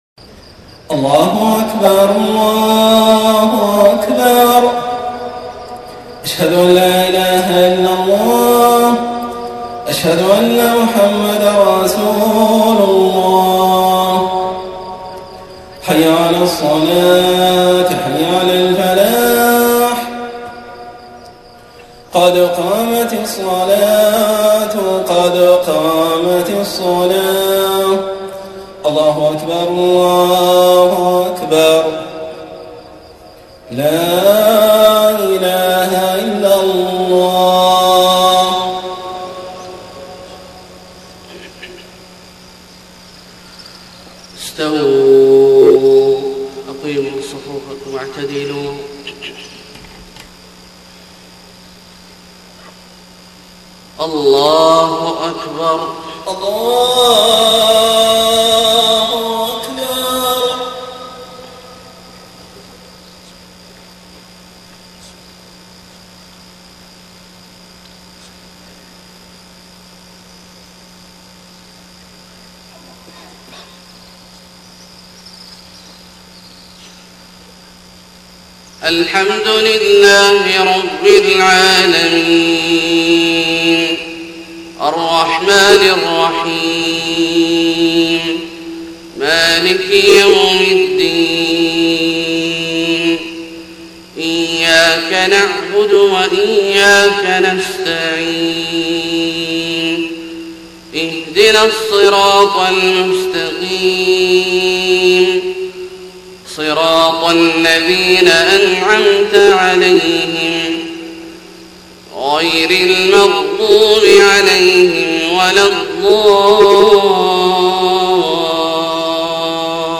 صلاة الفجر 9 ربيع الأول 1431هـ من سورة النساء {148-162} > 1431 🕋 > الفروض - تلاوات الحرمين